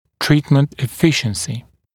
[‘triːtmənt ɪ’fɪʃnsɪ] [ə-][‘три:тмэнт и’фишнси] [э-]эффективность лечения